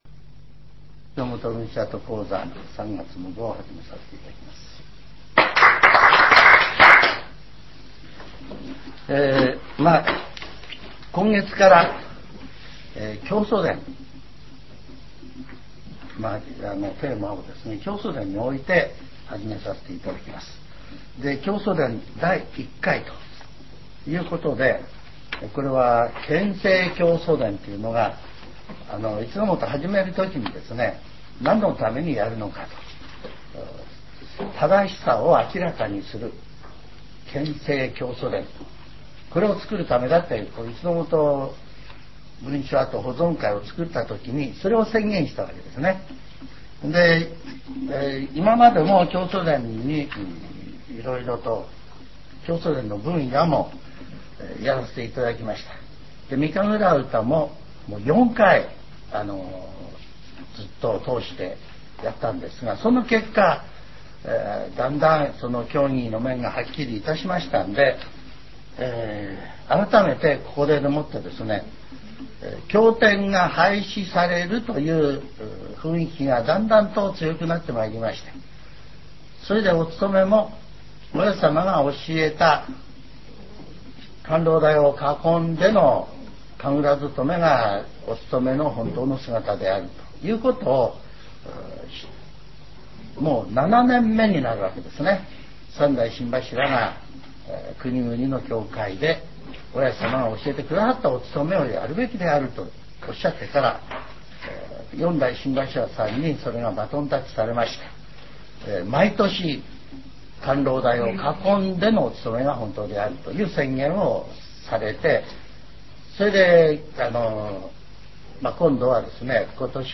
全70曲中7曲目 ジャンル: Speech